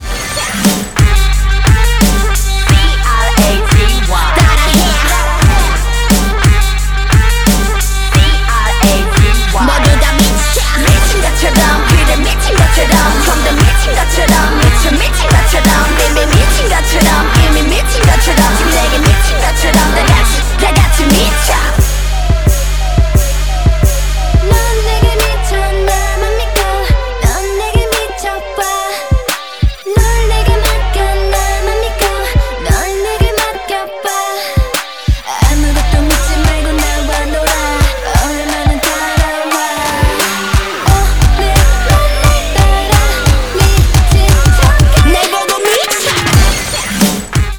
• Качество: 128, Stereo
громкие
качает